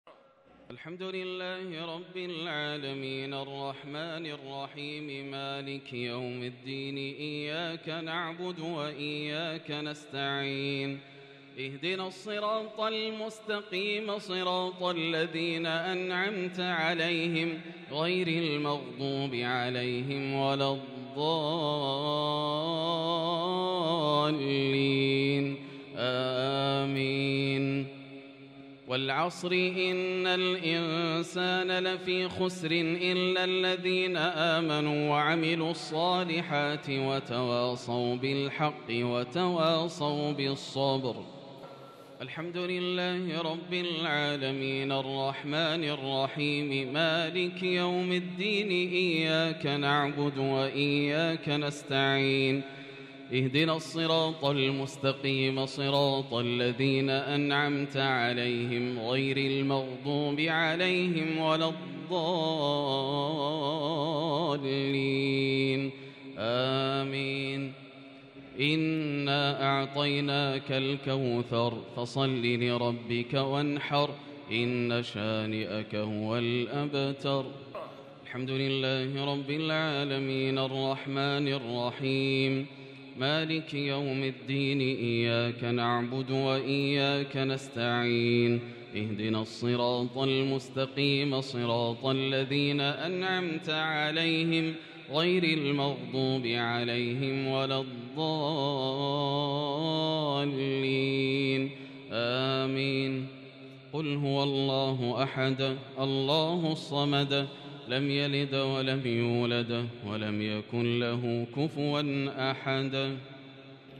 الشفع و الوتر ليلة 17 رمضان 1443هـ | Witr 17 st night Ramadan 1443H > تراويح الحرم المكي عام 1443 🕋 > التراويح - تلاوات الحرمين